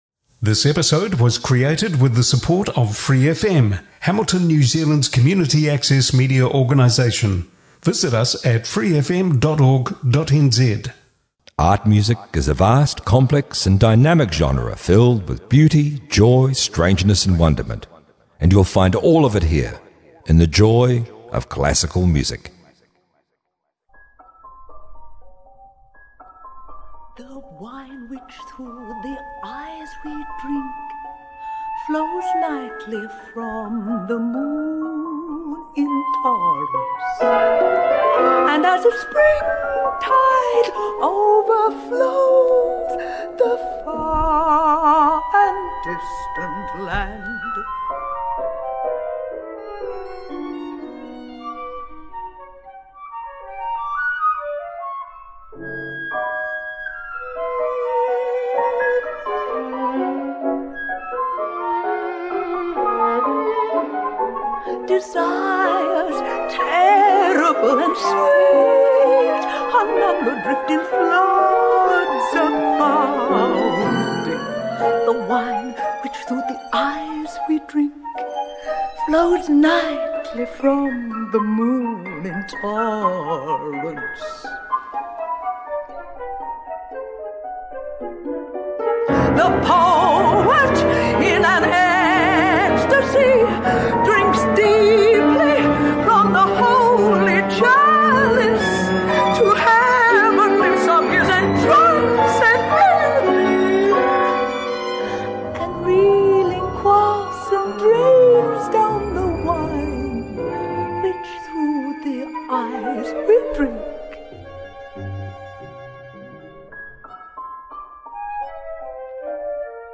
As it turns out, it’s another themed episode, The Art of the Voice. There’s bass singers, tenors, countertenors, sopranos, contralto’s baritones and a choir.